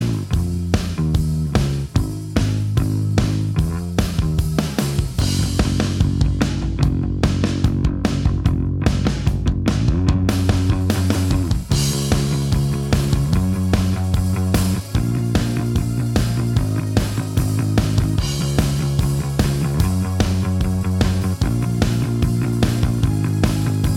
No Guitars Pop